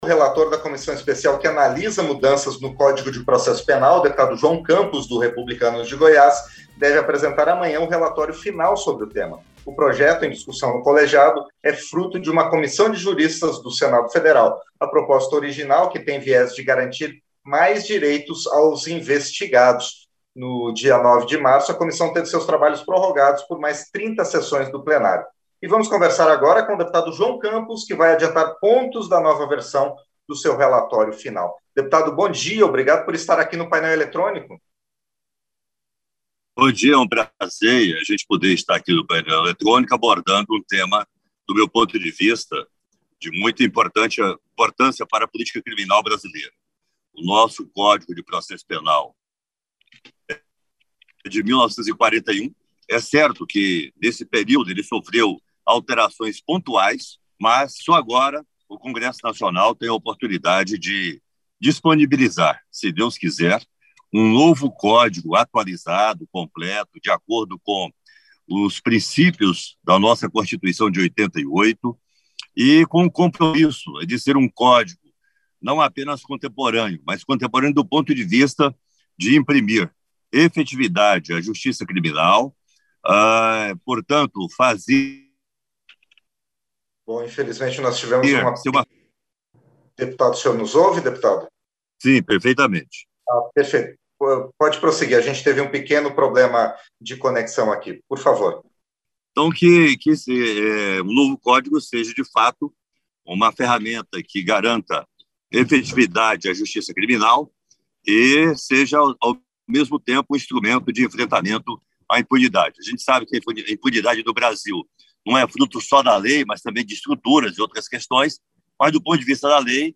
Entrevista – Dep. João Campos (REPUBLICANOS-GO)